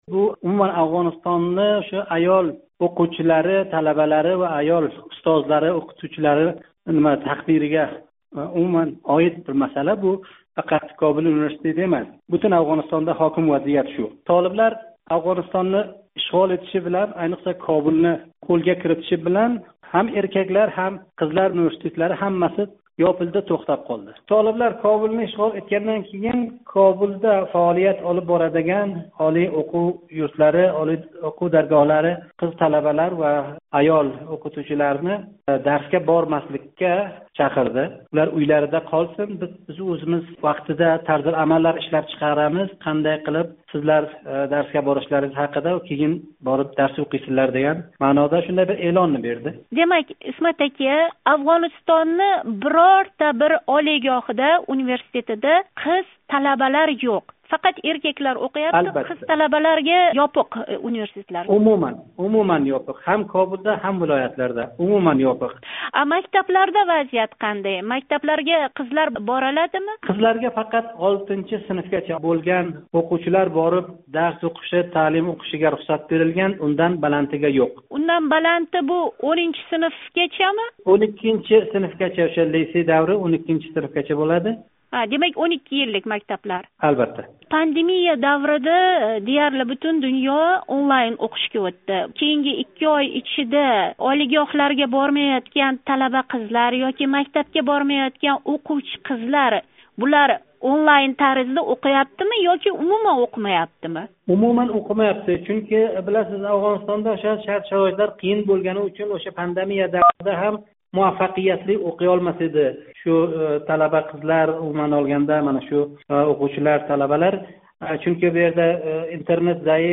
Афғонистонлик журналист